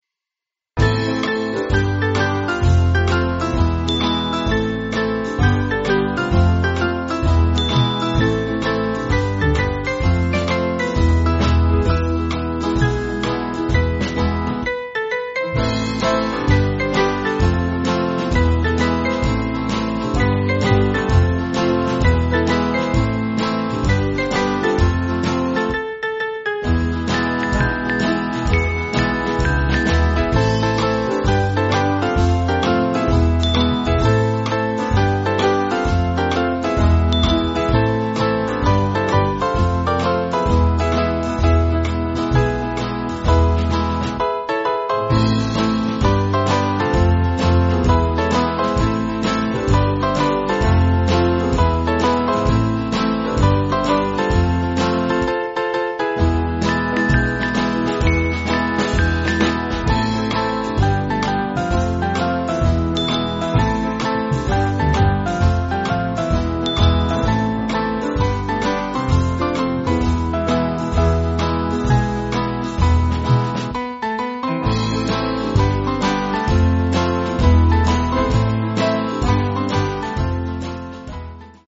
Swing Band
(CM)   5/Am